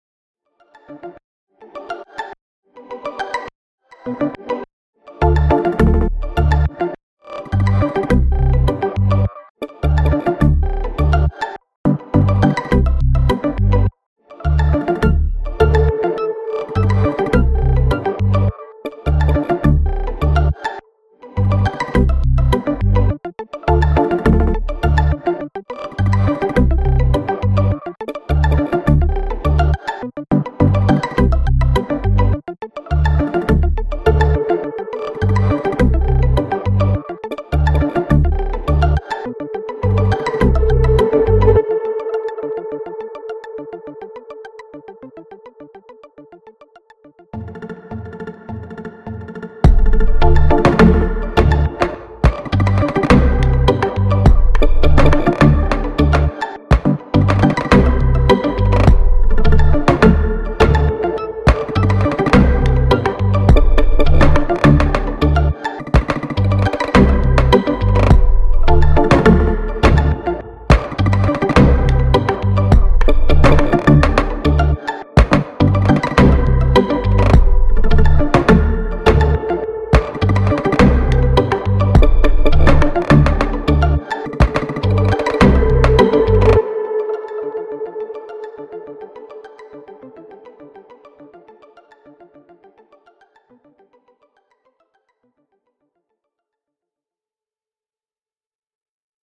echt coole melodie
nenn es ruhig "Rythm 'n' Noise"
Richtung Hard-Tech und zwar unter den gegebenen Contest Bedingungen
hab ich mir dieses mal das rauschen so erzeugt indem ich den reverb auf maximales decay eingestellt hab (20 sec) dass 100% wet und dann durch 2-3 zerren gedrückt bis es rauscht.